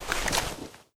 glowstick_holster.ogg